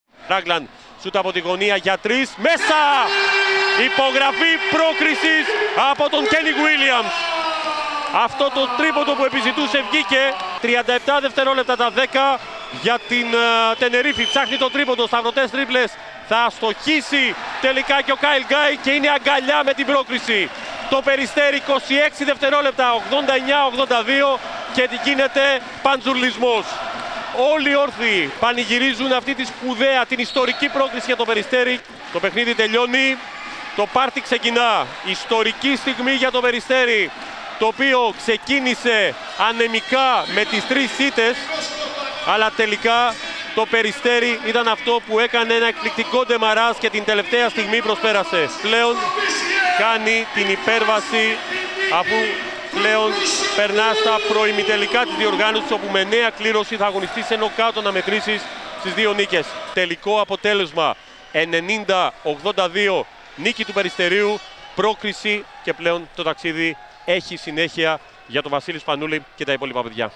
Η περιγραφή της συγκλονιστικής πρόκρισης του Περιστερίου (audio)